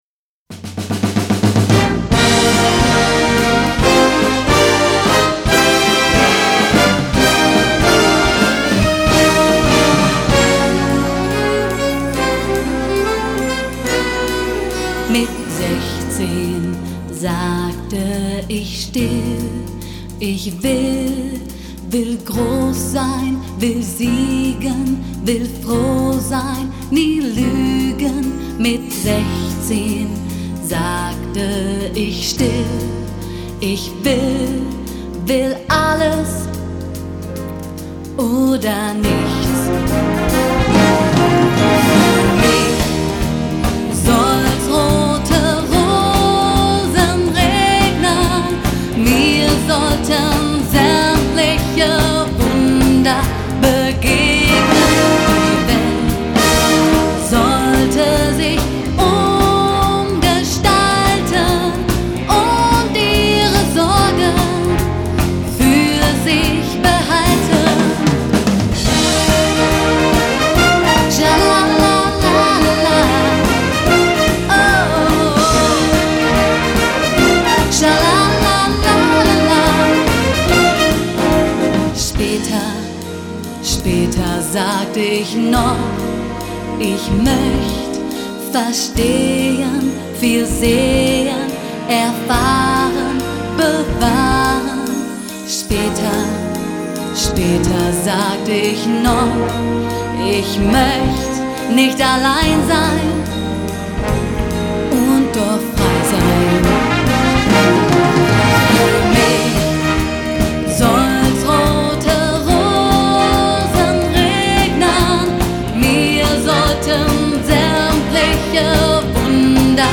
Gattung: für Solo Gesang und Blasorchester
Besetzung: Blasorchester
Rock-Shuffle Groove